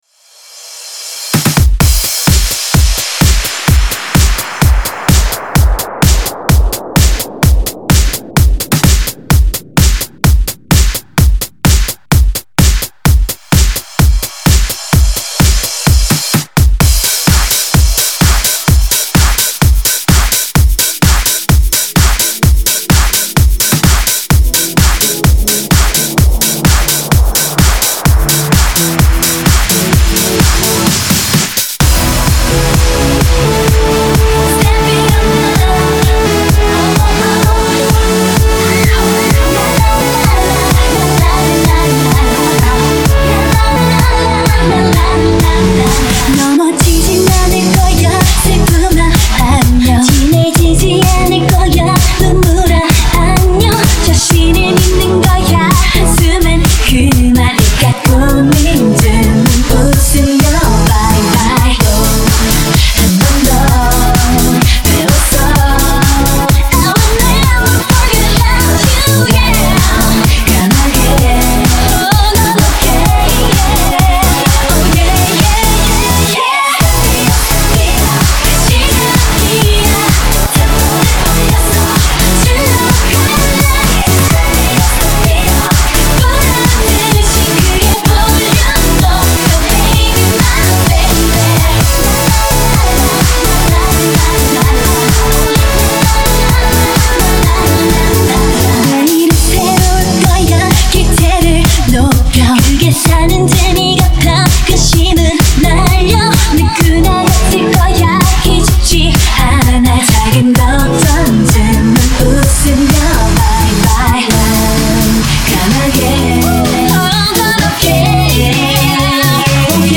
Genre(s): Electro-House